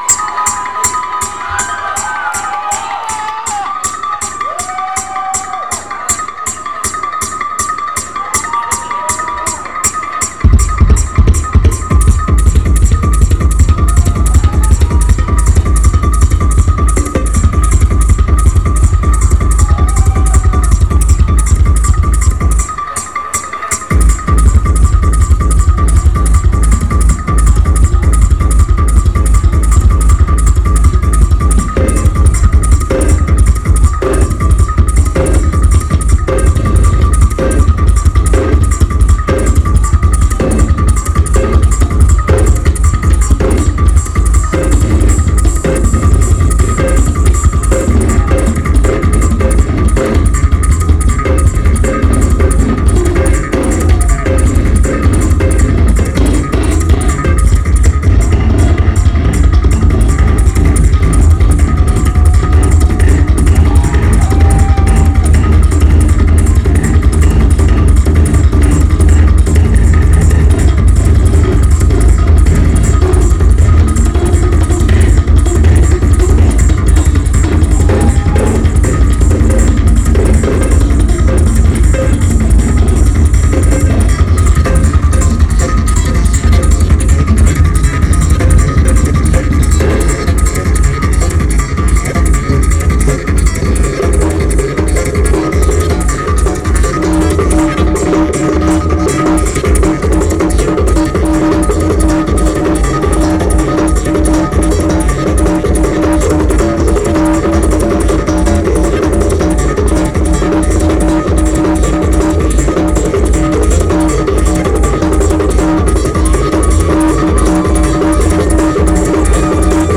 Drill & bass
location Phoenix, Arizona, USA venue Old Brickhouse Grill